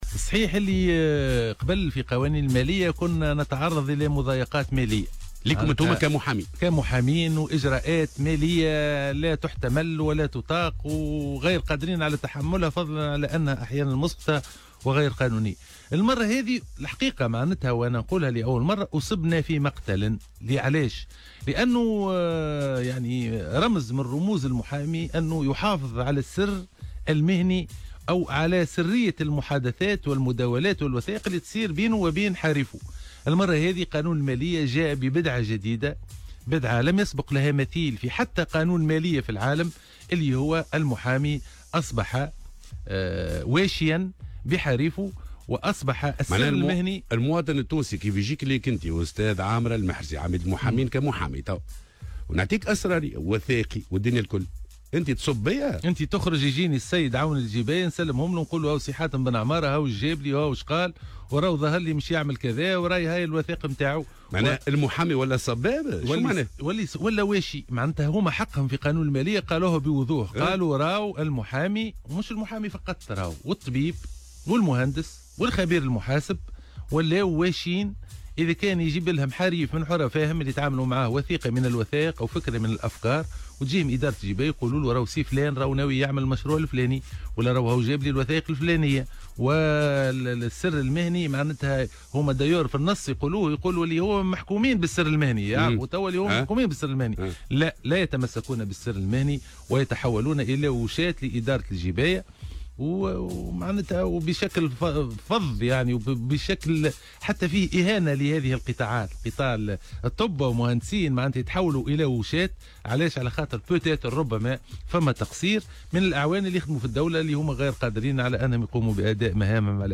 وأضاف في تصريح اليوم لـ"الجوهرة أف أم" أنهم لن يقبلوا بأن يكونوا مخبرين وواشين، مؤكدا أنهم سيواصلون تحركاتهم الاحتجاجية من أجل إسقاط هذا الفصل.